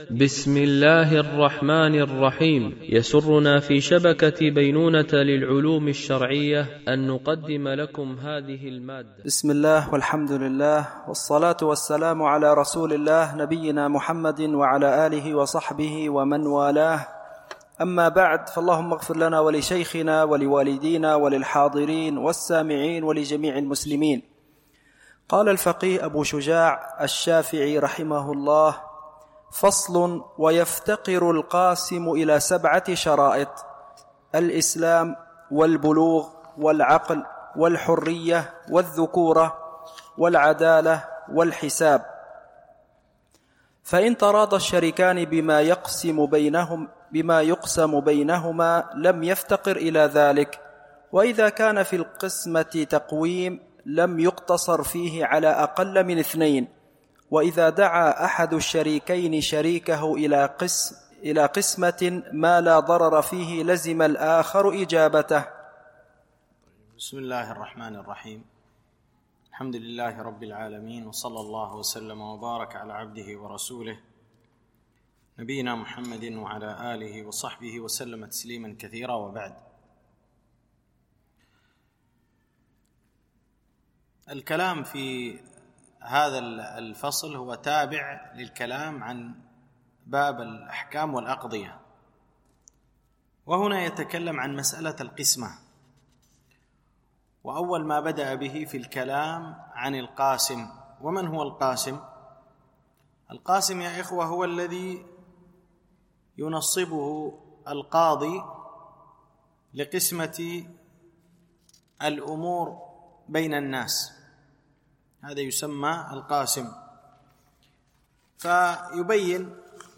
شرح متن أبي شجاع في الفقه الشافعي ـ الدرس 48